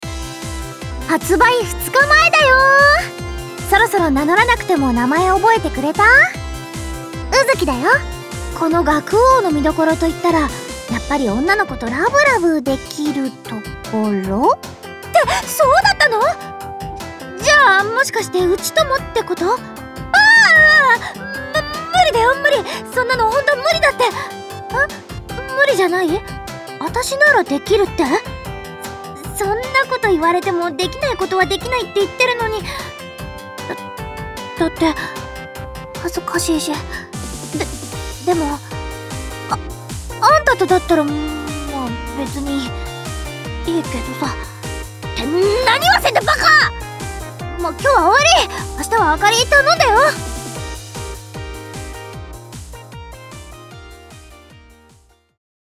「学☆王- THE ROYAL SEVEN STARS -」発売二日前カウントダウンボイスを公開しました！